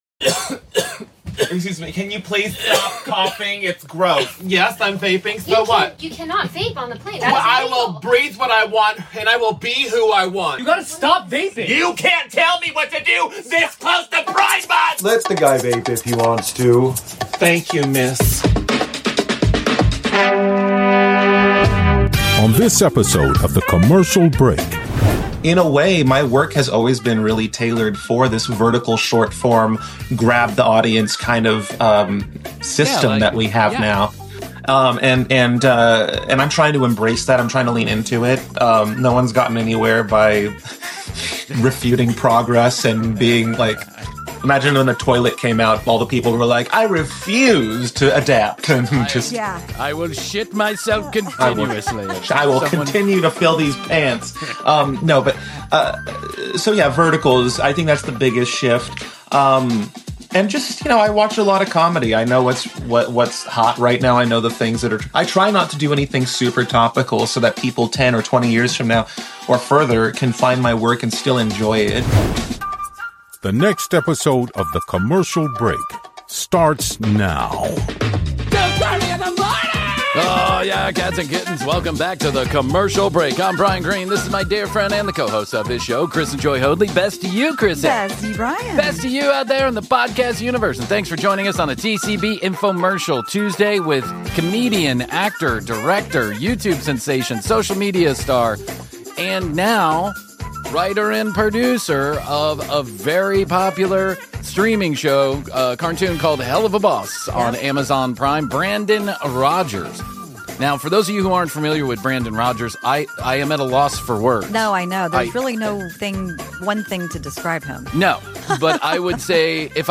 Comedian, filmmaker, and human fever dream Brandon Rogers drops into The Commercial Break this week to confirm what we’ve all suspected: there’s no off-switch.